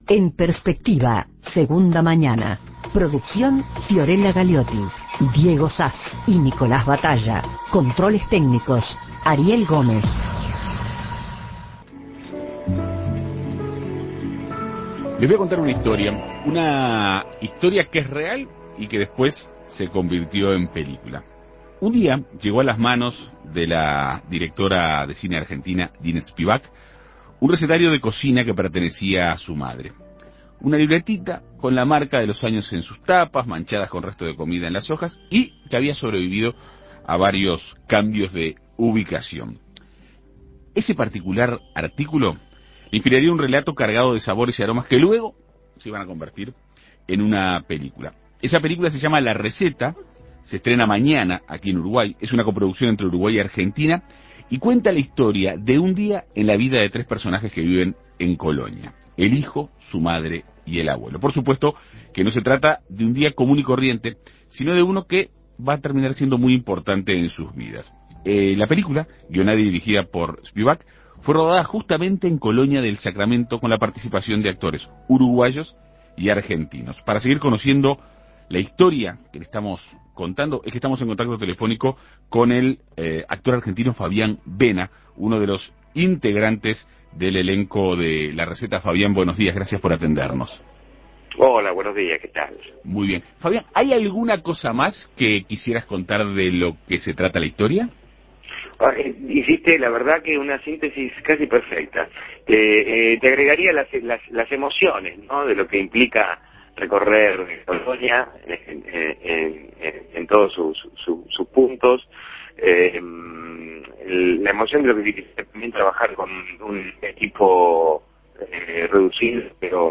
En Perspectiva Segunda Mañana entrevistó al actor argentino Fabián Vena, protagonista de "La Receta", película que cuenta la historia de un día en la vida de un hijo, su madre y el abuelo, que viven en Colonia. No se trata de un día común y corriente, sino de uno que terminará siendo muy importante en sus vidas.